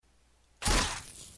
zeus csgo Meme Sound Effect
This sound is perfect for adding humor, surprise, or dramatic timing to your content.